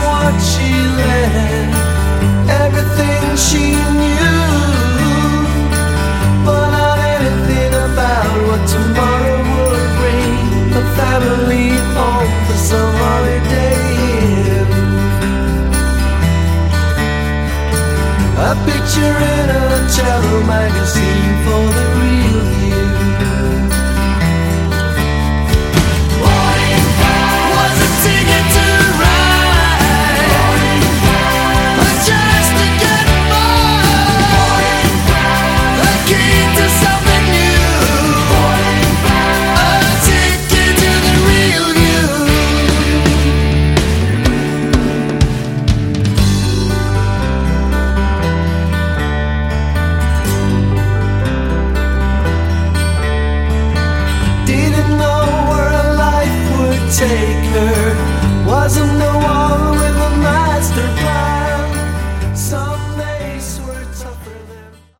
Category: Melodic Rock
Drums
Vocals, Guitars
Guitars, Backing Vocals
Bass